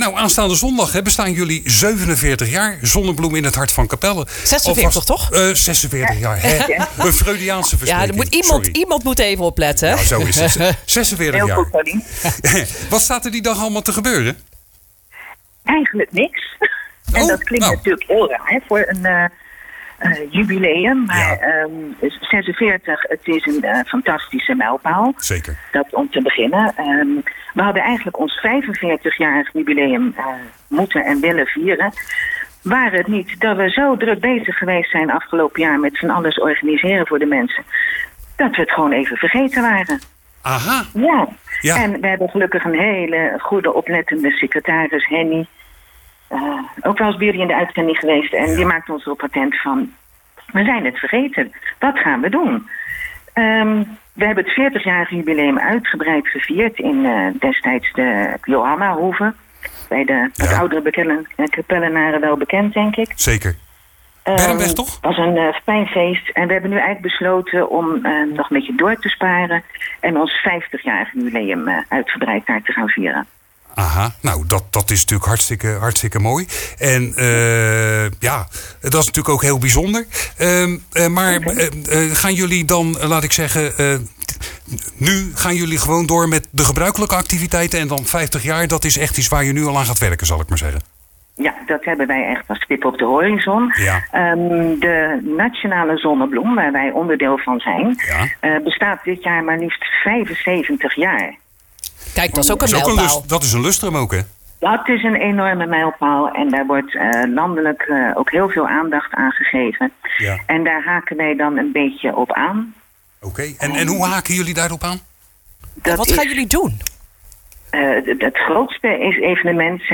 Alle reden voor een gesprek met voorzitster